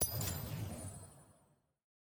sfx-tft-lobby-doubleup-swap-self.ogg